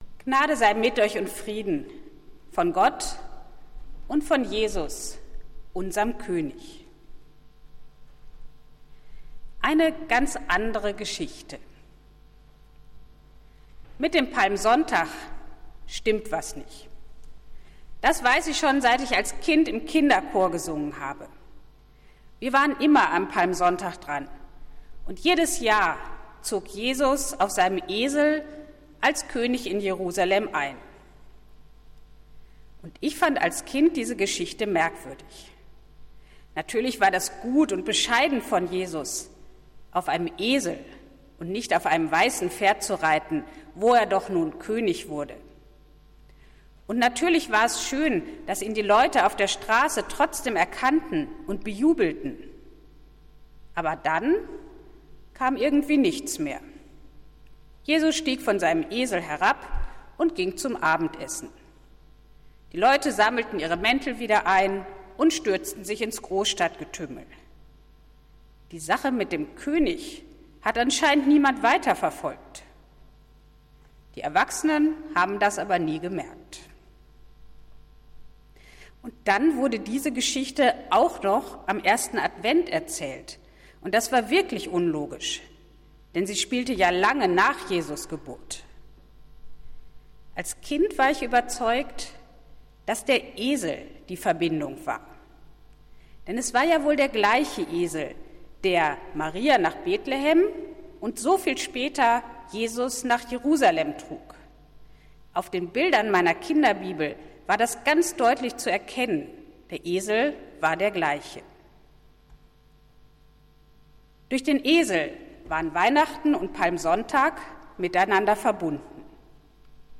Predigt des Gottesdienstes aus der Zionskirche vom Sonntag, 10.04.2022
Wir haben uns daher in Absprache mit der Zionskirche entschlossen, die Predigten zum Nachhören anzubieten.